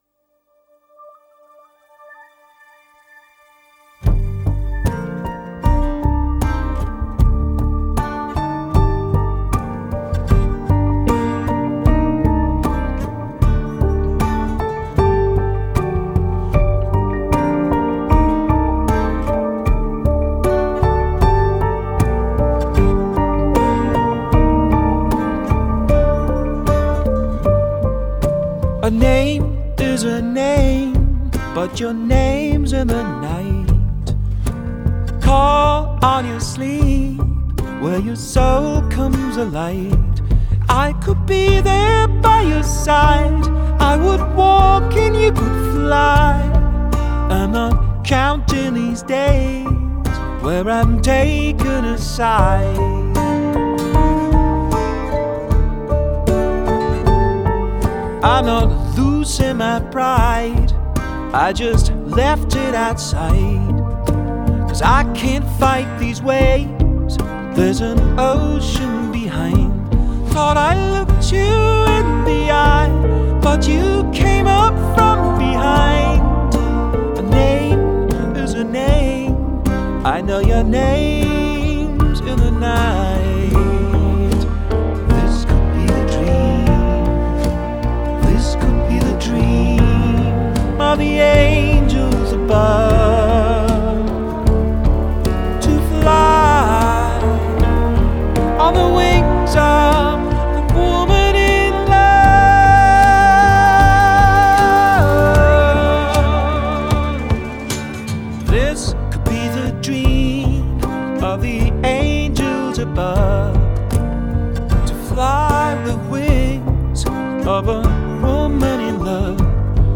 поп-музыка, рок-музыка